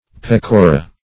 Search Result for " pecora" : The Collaborative International Dictionary of English v.0.48: Pecora \Pec"o*ra\, n. pl.